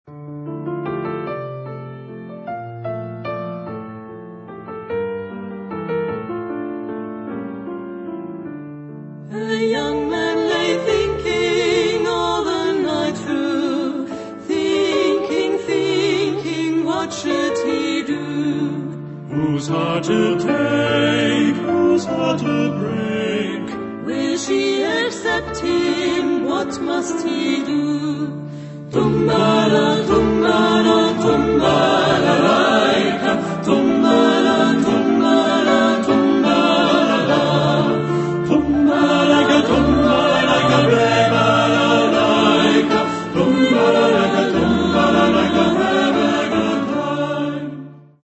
Genre-Style-Form: Secular ; Traditional
Mood of the piece: fast
Type of Choir: SATB  (4 mixed voices )
Instrumentation: Piano  (1 instrumental part(s))
Tonality: F major